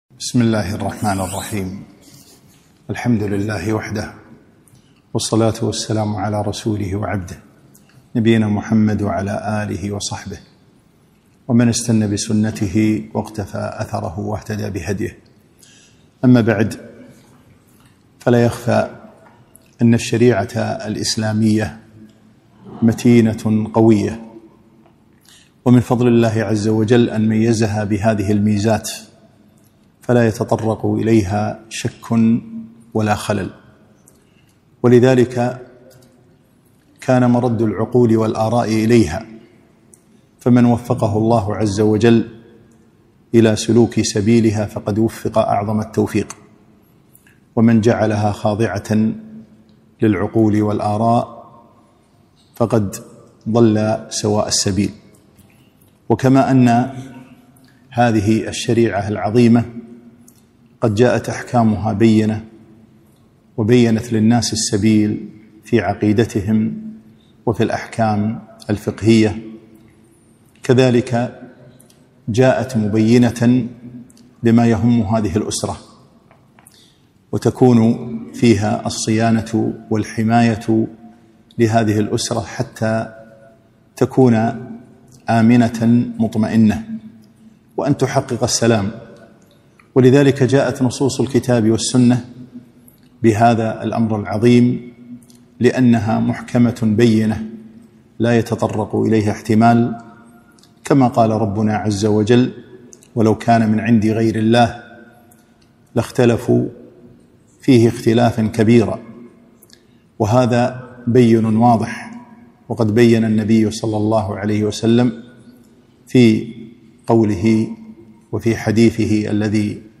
محاضرة - صيانة الأسرة